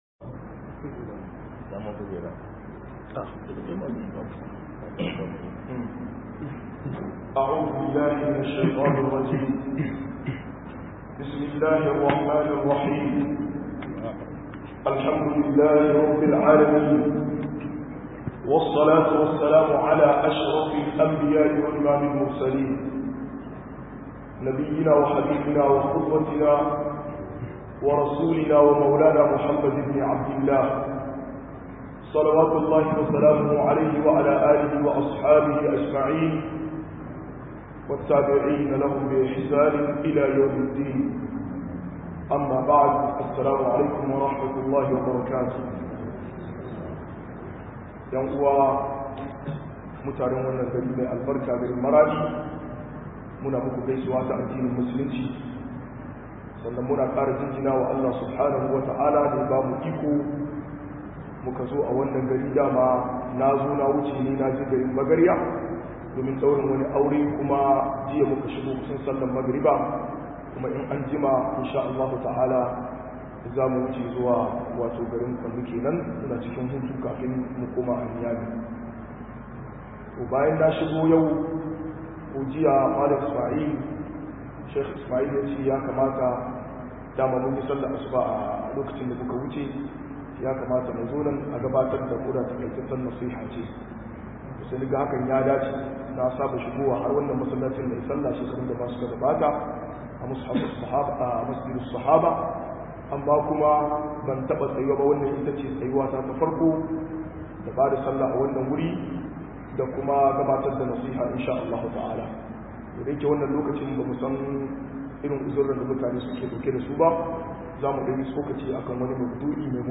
201-Wasu Kurakurai a Tarbiyya 2 - MUHADARA